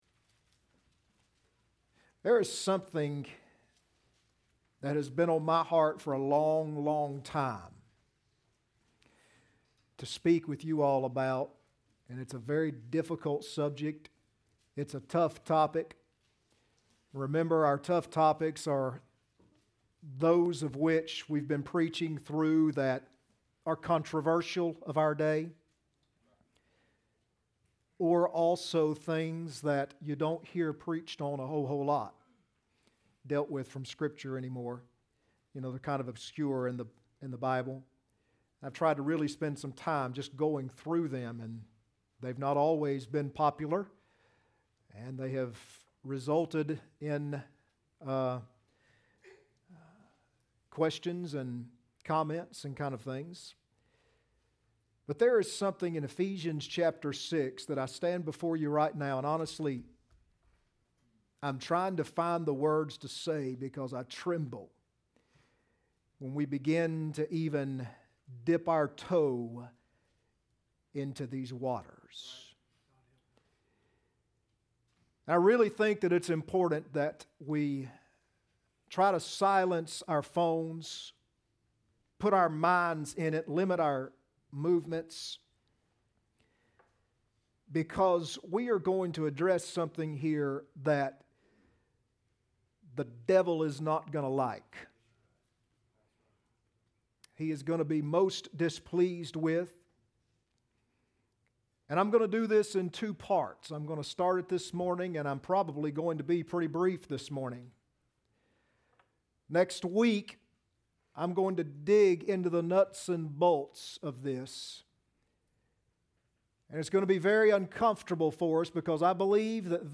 Ephesians 6:10-12 Service Type: Sunday Morning Next Sermon